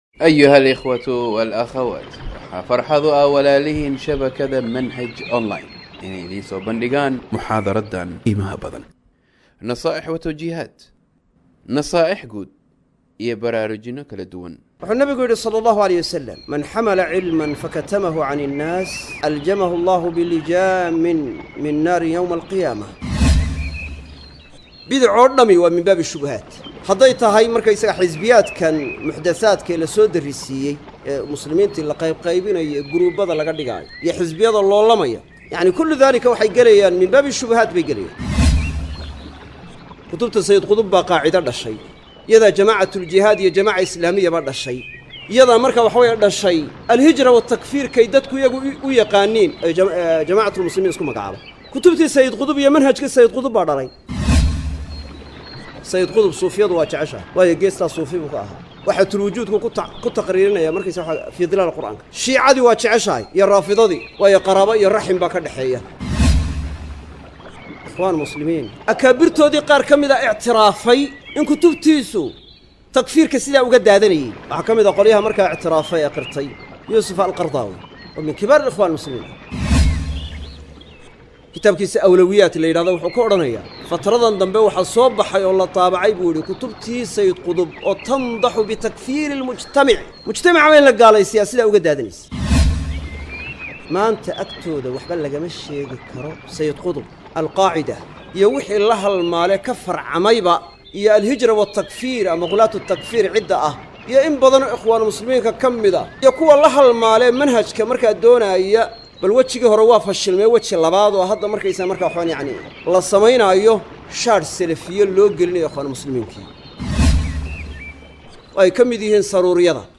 Muxaadarooyin